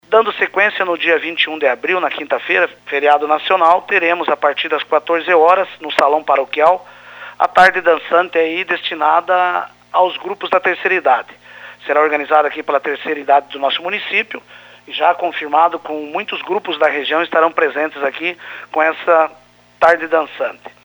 RAUL-03-FALA-DA-TARDE-DANÇANTE-NA-QUINTA-FEIRA-21.mp3